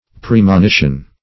Premonition \Pre`mo*ni"tion\, n. [L. praemonitio.